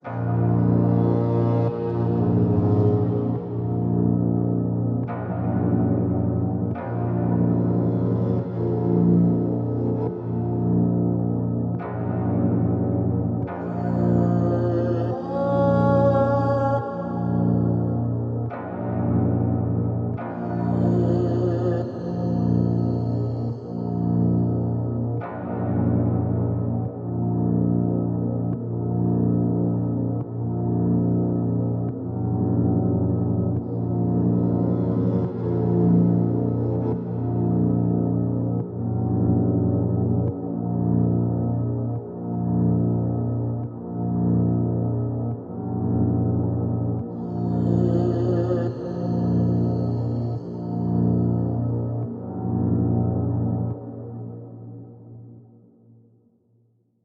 UK DRILL LOOPS